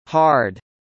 アメリカ英語では、Rは舌を巻いたしっかりした発音になるため、日本人にとって発音するのが難しい音になります。
たとえば「hard」と言うとき、アメリカ英語ではかすかに「r」のサウンドが聞こえますが、イギリス英語ではカタカナの発音の「ハード」に近い感じになります。
【アメリカ英語　hard】